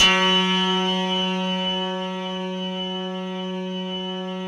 RESMET F#3-R.wav